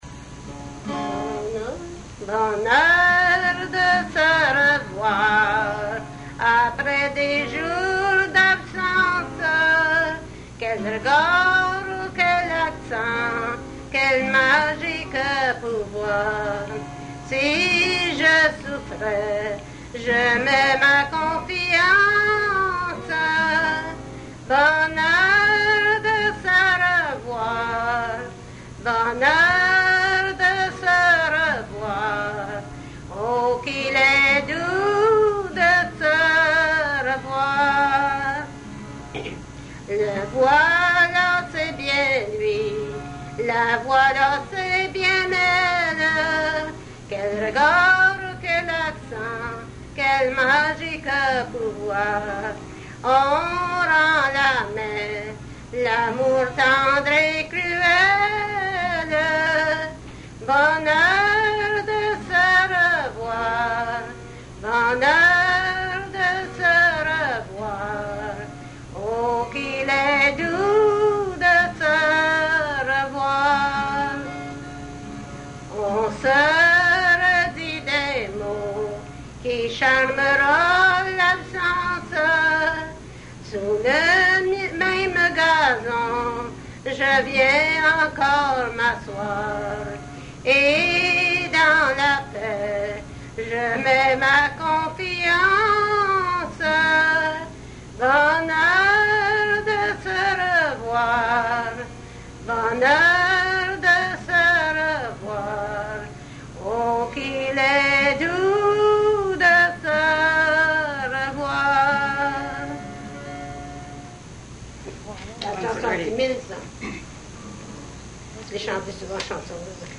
Folk Songs, French--New England
Excerpt from interview